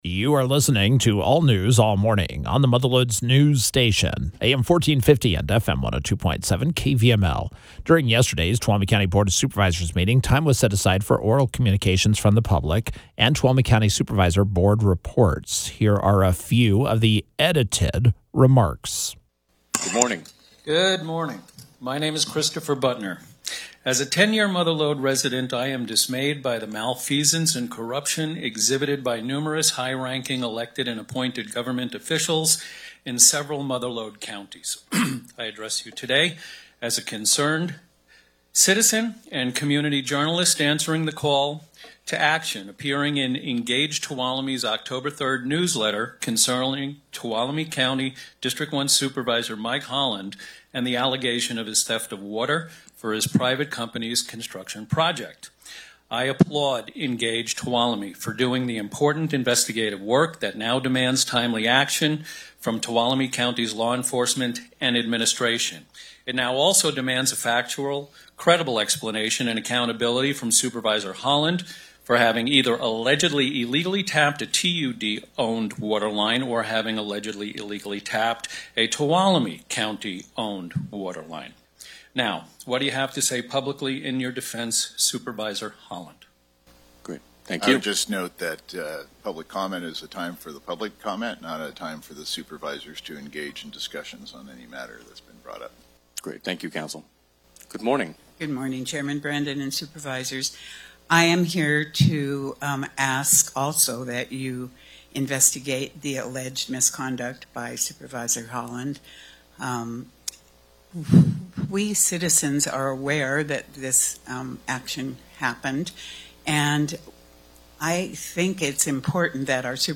On Tuesday October 7th, Tuolumne County Supervisor Mike Holland defended himself against a few attacks from the general public during Oral Communications.